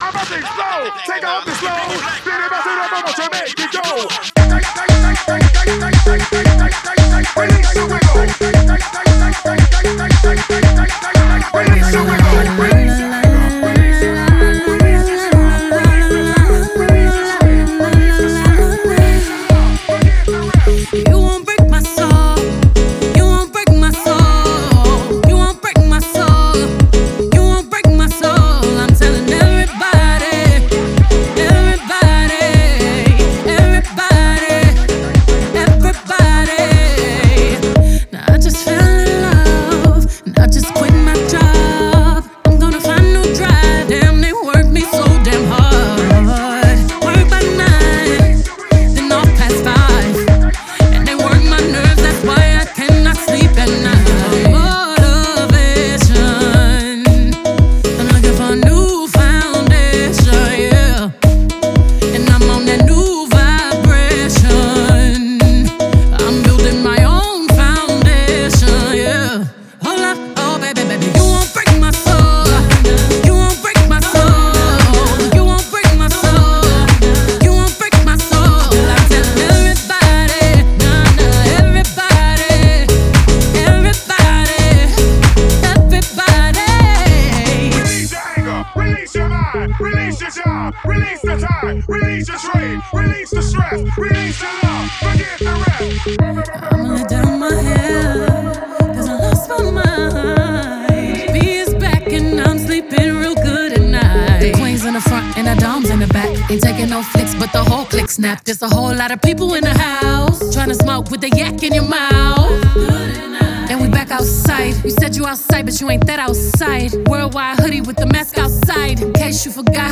La animada y contagiosa
y está impulsada por un sample de un clásico House de los 90